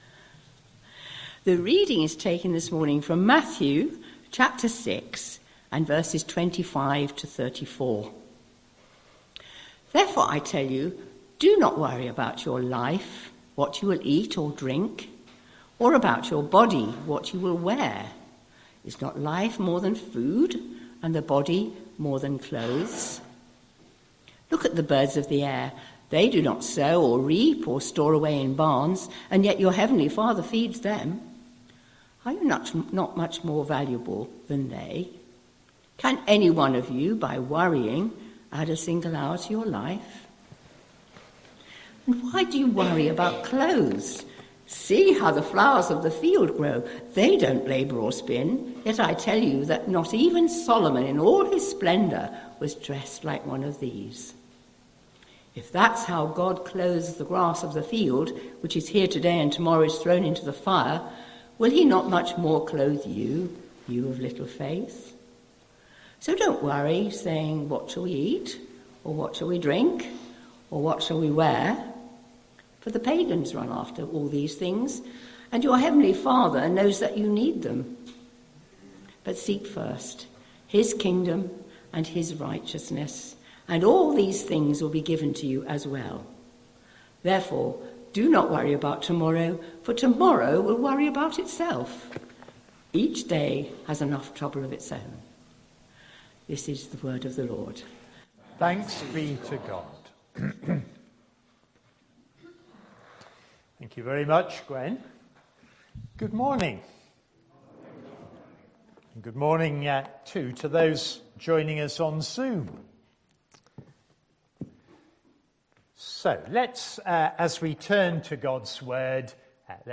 8th February 2026 Sunday Reading and Talk - St Luke's